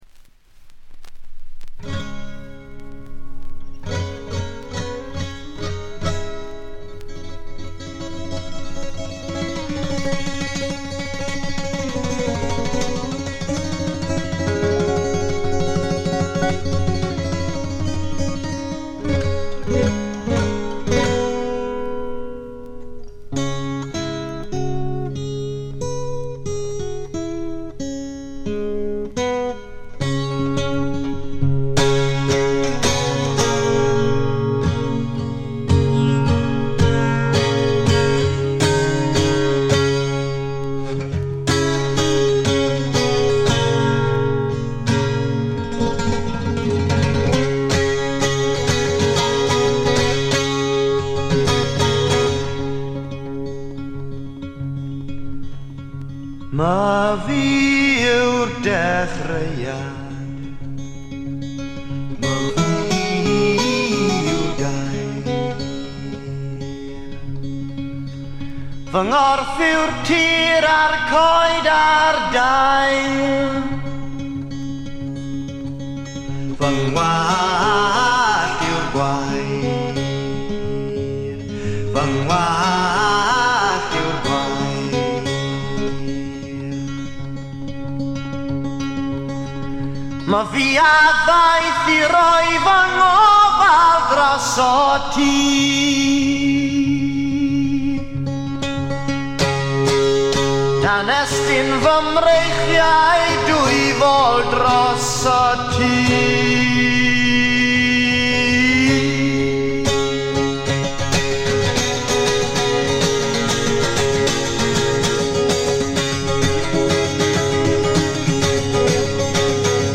わずかなノイズ感のみ。
内容的にはおそろしく生々しいむき出しの歌が聞こえてきて、アシッド・フォーク指数が異常に高いです。
試聴曲は現品からの取り込み音源です。
Vocals, Acoustic Guitar